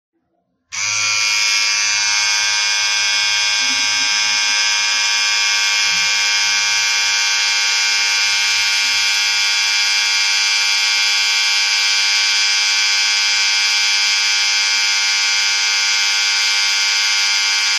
Hair Clippers